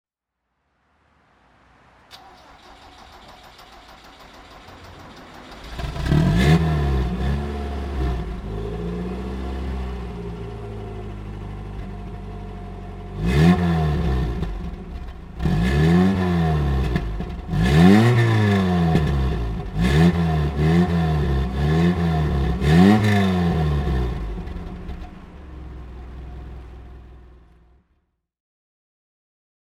Toyota Corona Liftback 1800 GL (1980) - Starten und Leerlauf
Toyota_Corona_Liftback_1980.mp3